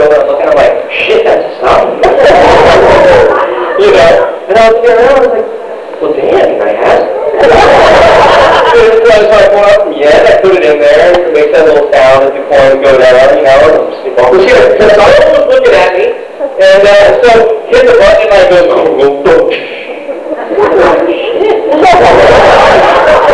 Gatecon 2003